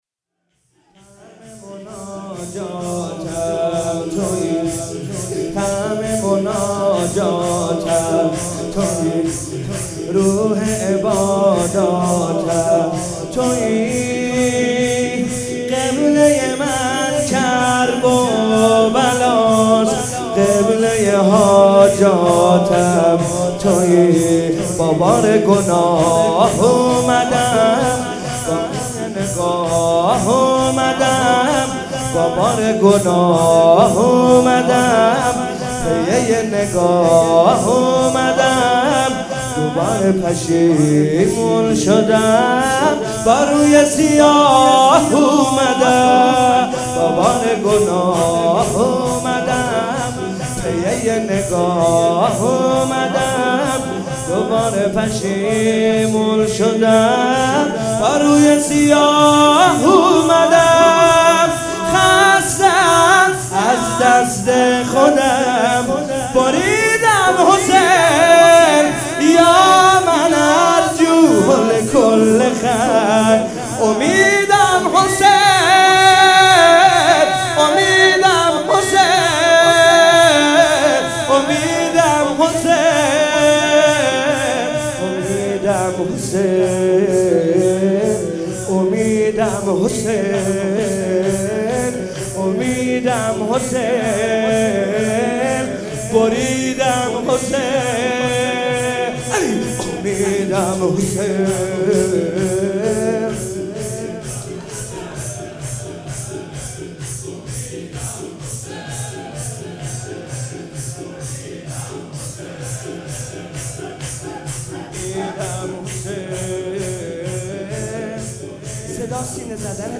مناسبت : وفات حضرت زینب سلام‌الله‌علیها
قالب : شور